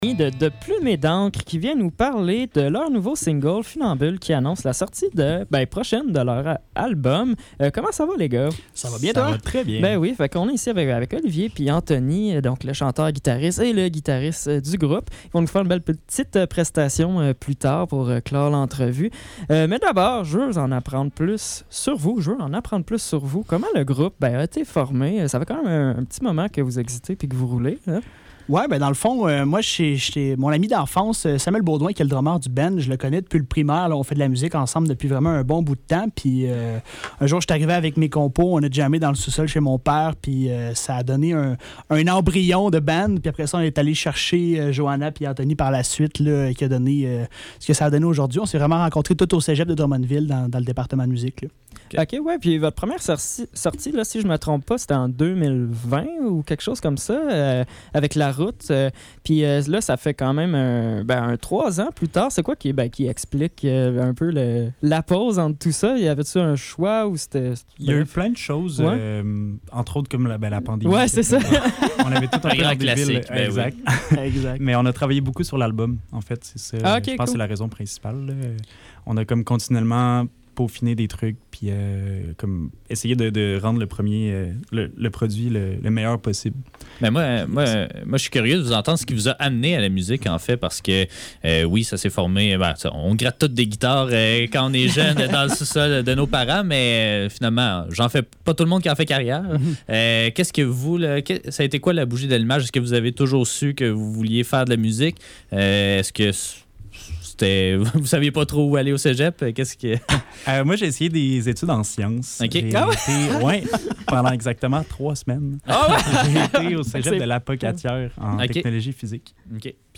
entrevue-de-plumes-et-d-encre.mp3